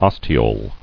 [os·ti·ole]